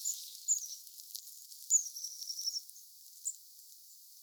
pyrstötiaiset laulavat kovallakin pakkasella talvella?
joitakin pyrstötiaisen laulusäkeitä.
Ehkäpä nuoret koiraat laulavat.
pyrstotiaiset_laulavat_talvellakin.mp3